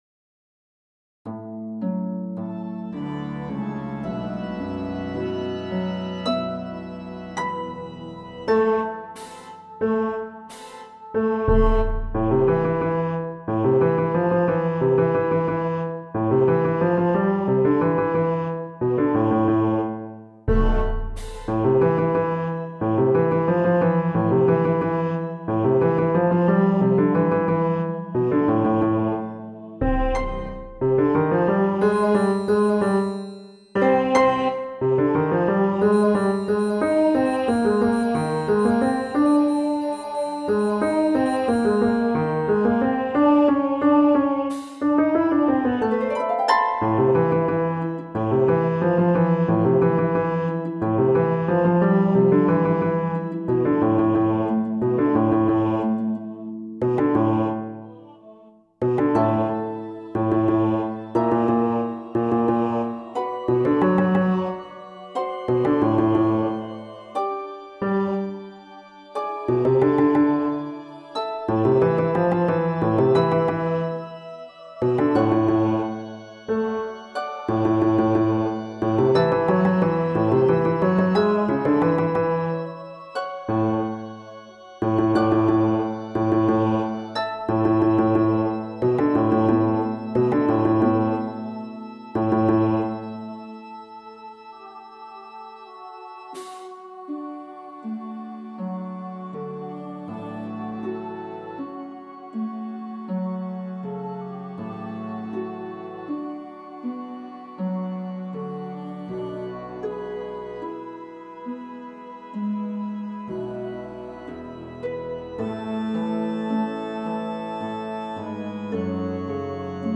Welcome to the schedule and download site for practice files, for the use of the Chamber Choir at First Universalist Church of Denver (Colorado).
Bass at m 62
ChichesterPsalms2-bass-at-m.62.mp3